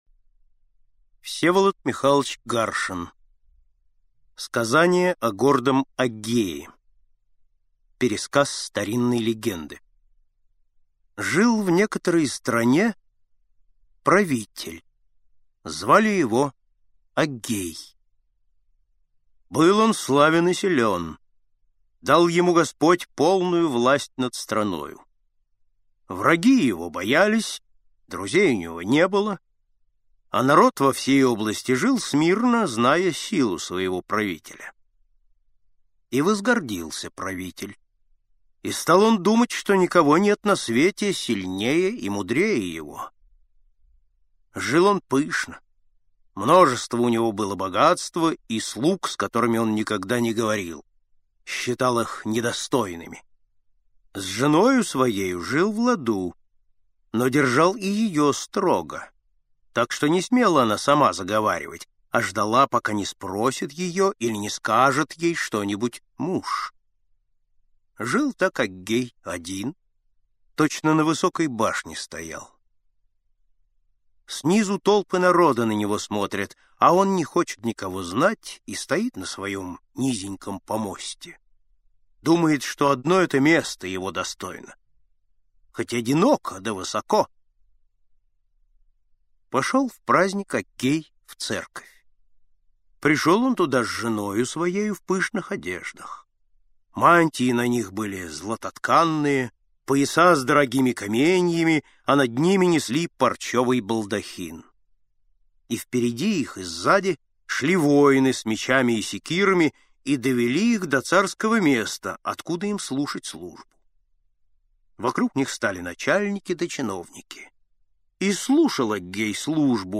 Сказание о гордом Аггее - аудиосказка Всеволода Гаршина - слушать онлайн